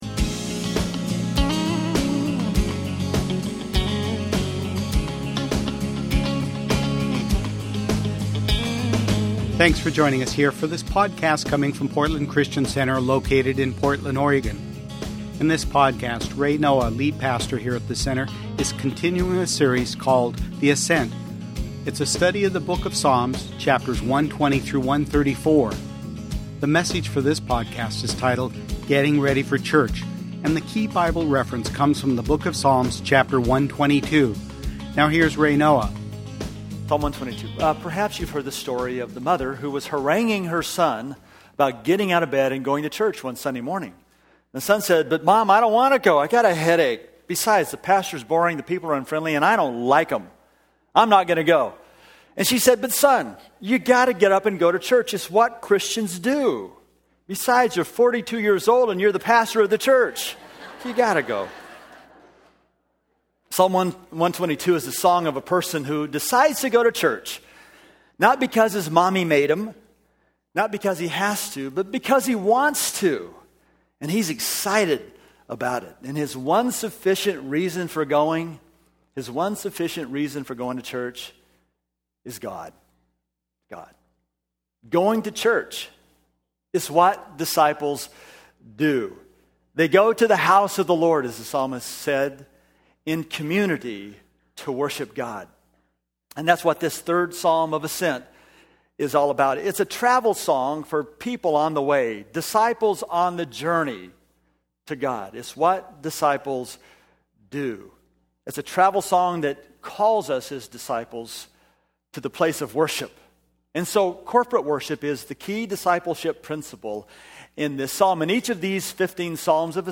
Sunday Messages from Portland Christian Center The Ascent – Psalms 120-134: Pt 3 – Getting Ready for Church Mar 18 2012 | 00:38:35 1x 00:00 / 00:38:35 Subscribe Share Spotify RSS Feed Share Link Embed